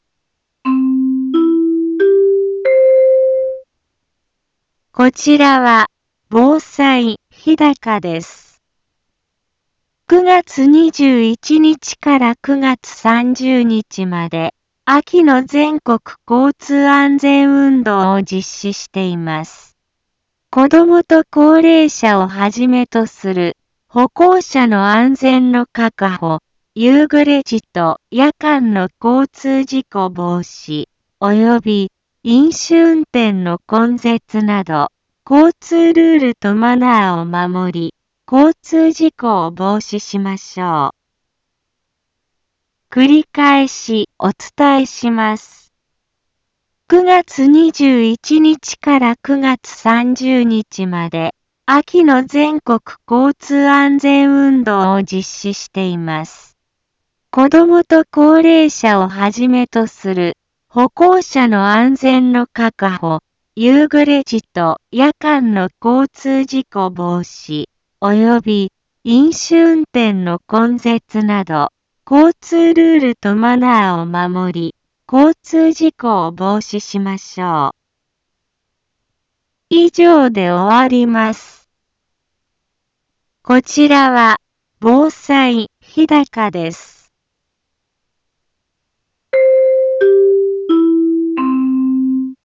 Back Home 一般放送情報 音声放送 再生 一般放送情報 登録日時：2023-09-21 15:03:17 タイトル：秋の全国交通安全運動のお知らせ インフォメーション： 9月21日から9月30日まで、「秋の全国交通安全運動」を実施しています。